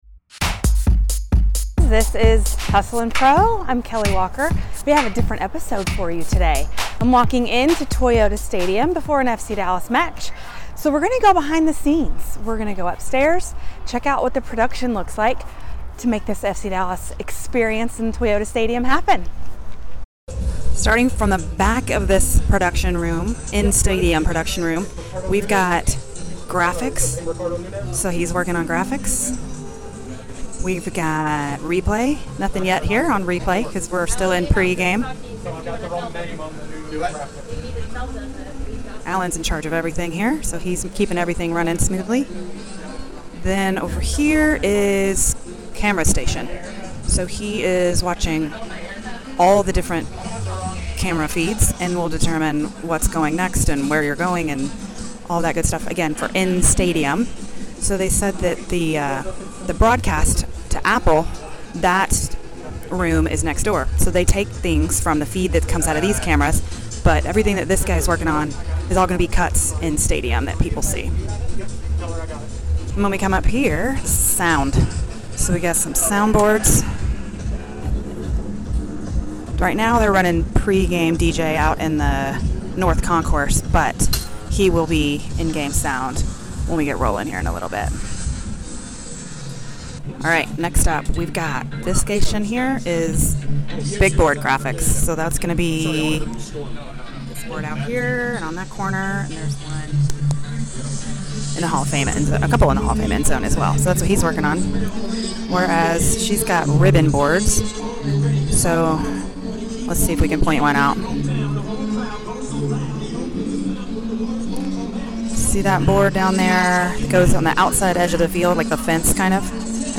This time we have behind-the-scenes access at Toyota Stadium during Star Wars Night at FC Dallas.